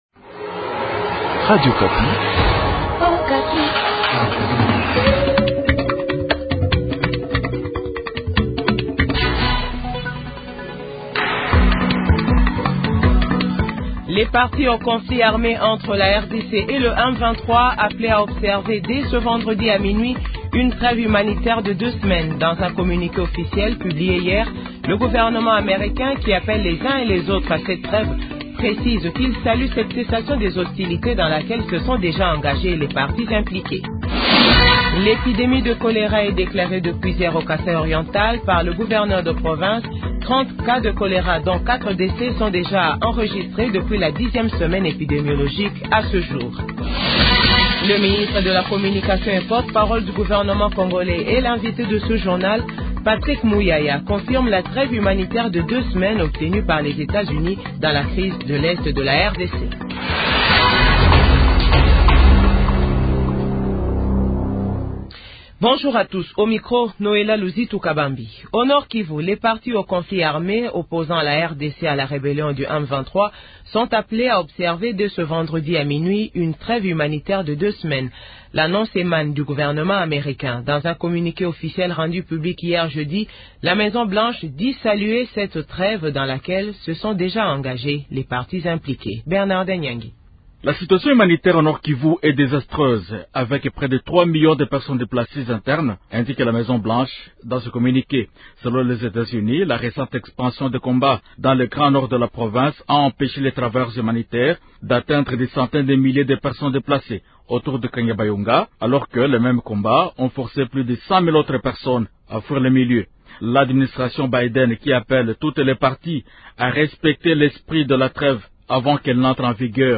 JOURNAL FRANCAIS 12H00